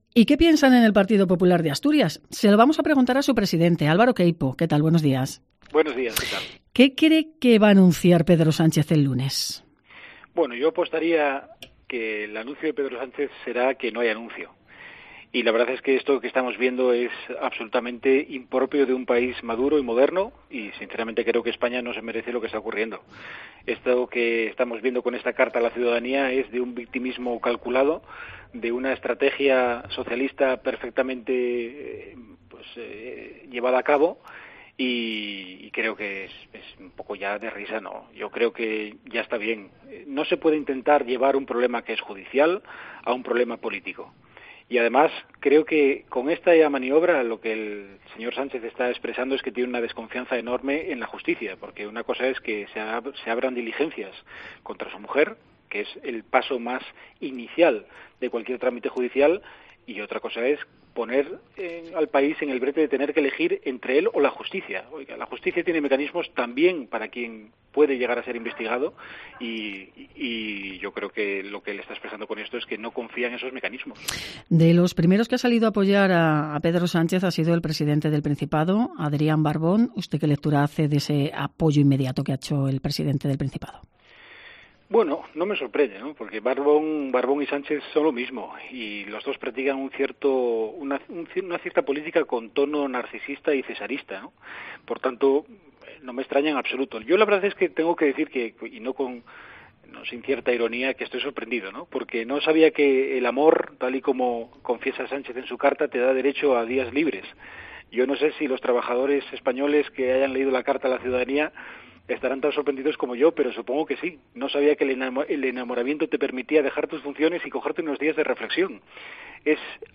Entrevista a Álvaro Queipo sobre el periodo de reflexión de Pedro Sánchez